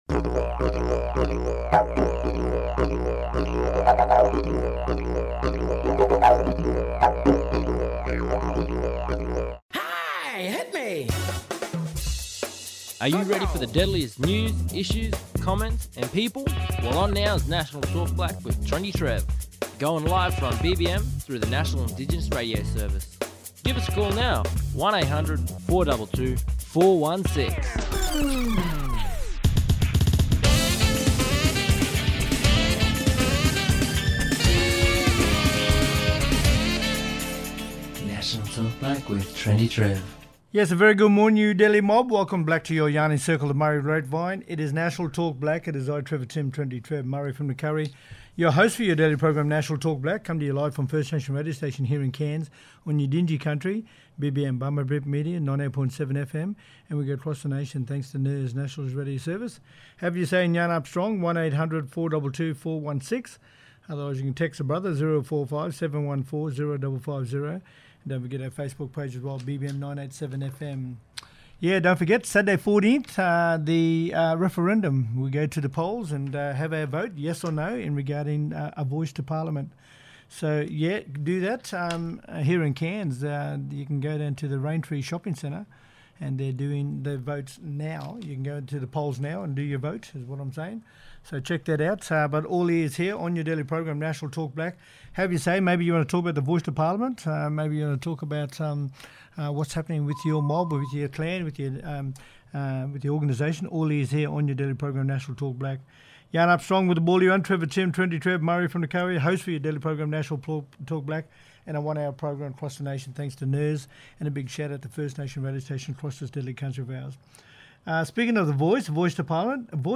Pat Anderson AO, Co-Chair The Uluru Statement from the Heart, talking about how the Voice information has been released in 30 First Nations languages. The Uluru Dialogue has worked with translation services in Queensland, Western Australia and the Northern Territory to translate information about the referendum, what the Voice is and encourage people in these communities to vote.